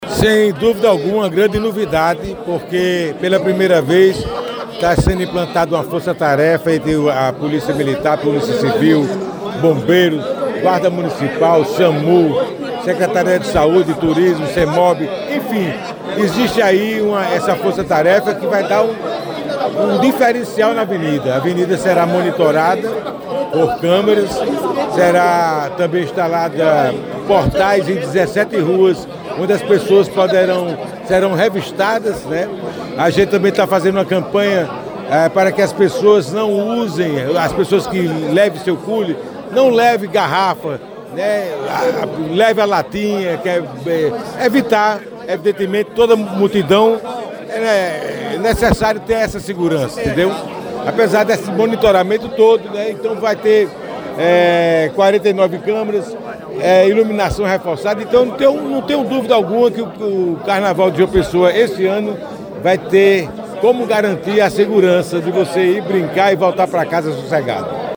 Em entrevista ao programa Correio Debate, da Rádio Correio 98 FM, ele falou sobre o novo esquema de segurança que irá monitorar por meio de câmeras de segurança toda a avenida durante os festejos.